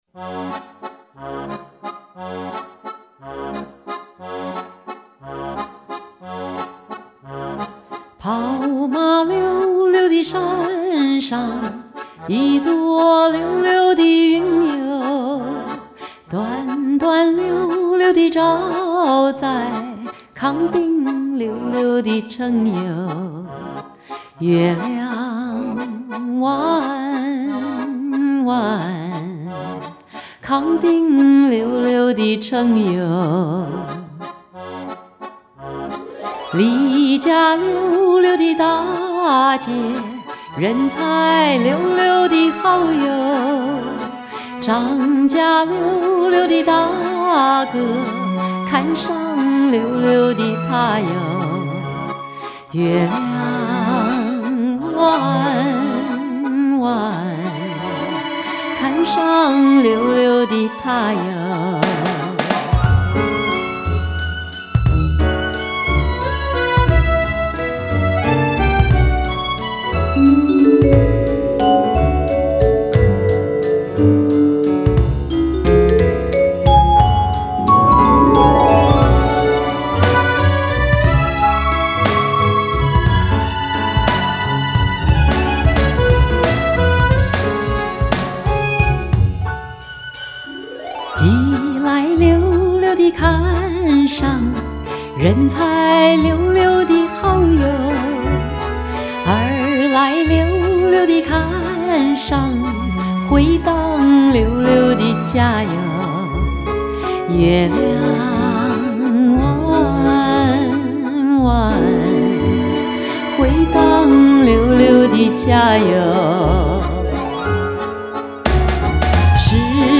发首康定情歌，是蔡琴唱的，总觉得效果不好。